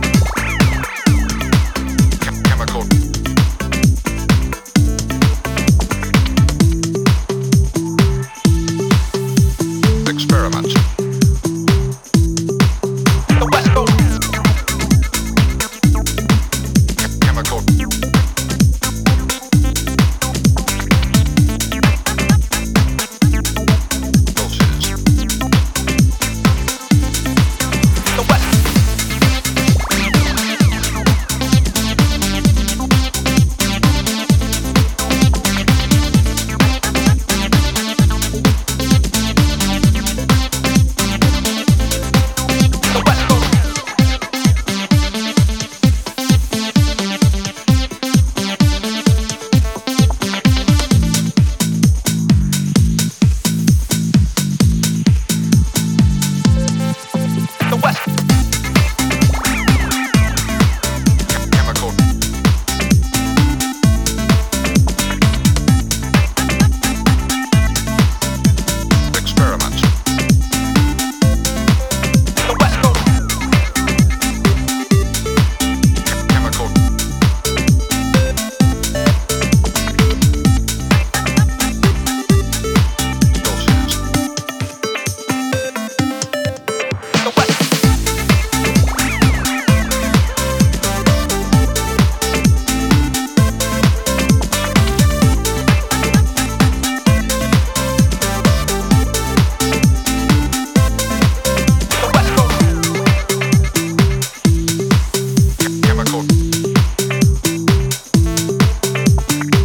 With a 90’s ravey
sizzling basslines and some great breaks